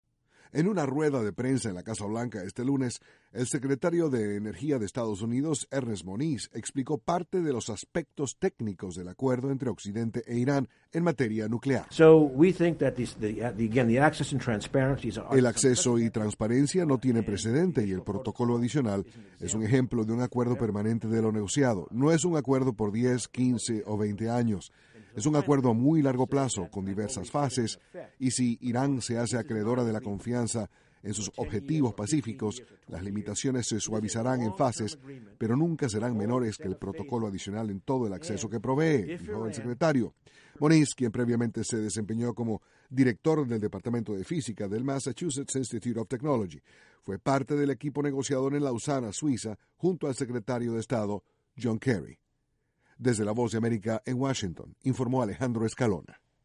El Secretario de Energía de Estados Unidos dijo que el acuerdo logrado con Irán es “a muy largo plazo”. Desde la Voz de América, en Washington